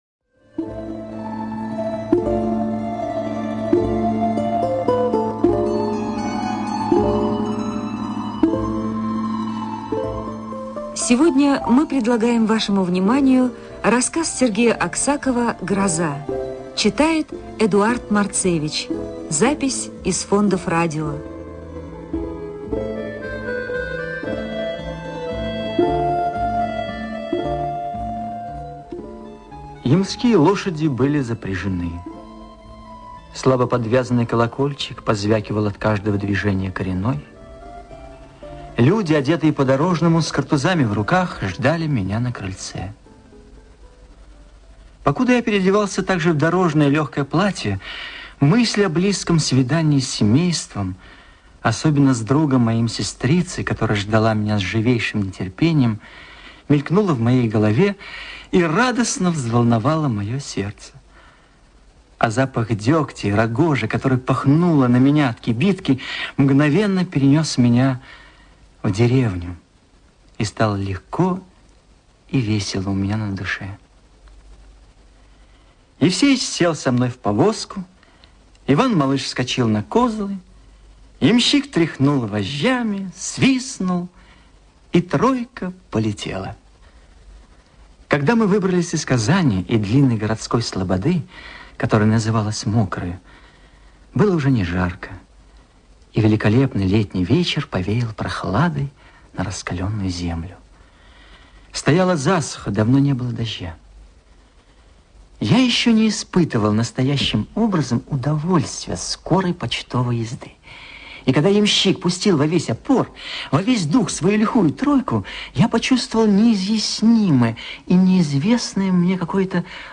2. «Сергей Аксаков – Гроза (рассказ чит. Э.Марцевич)» /
Aksakov-Groza-rasskaz-chit.-E.Martsevich-stih-club-ru.mp3